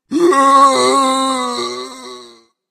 zombie_die_3.ogg